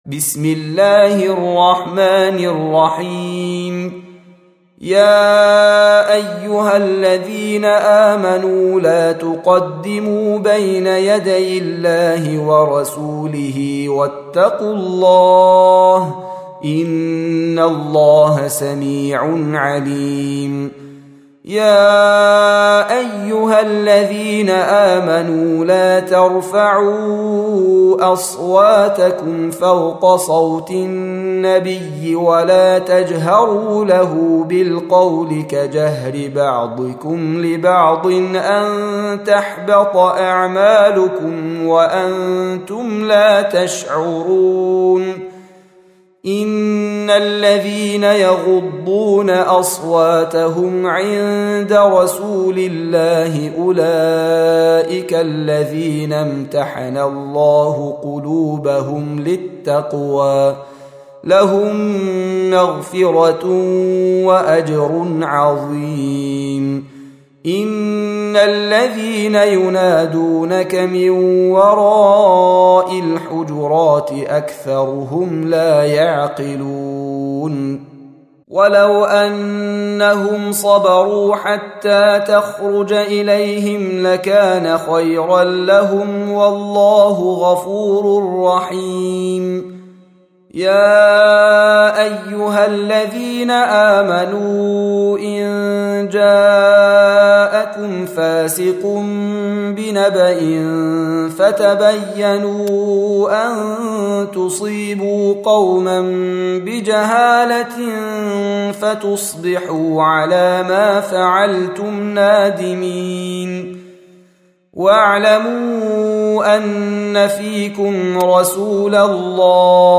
Surah Sequence تتابع السورة Download Surah حمّل السورة Reciting Murattalah Audio for 49. Surah Al-Hujur�t سورة الحجرات N.B *Surah Includes Al-Basmalah Reciters Sequents تتابع التلاوات Reciters Repeats تكرار التلاوات